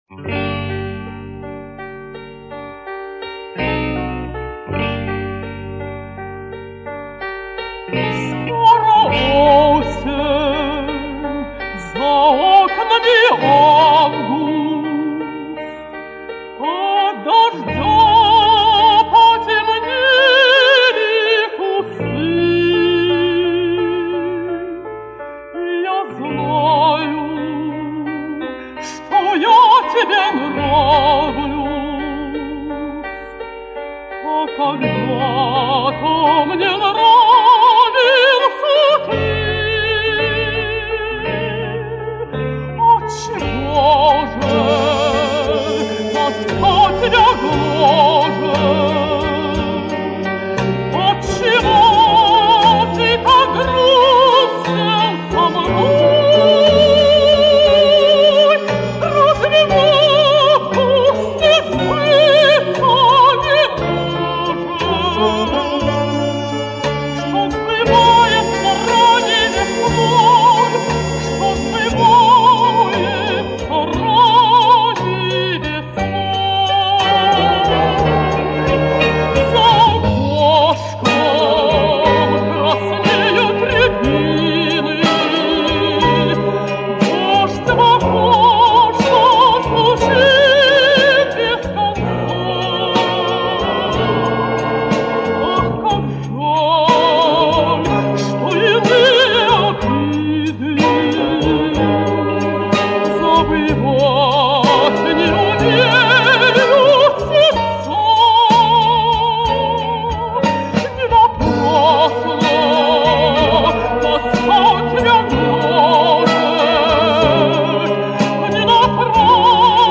в академическом стиле
контральто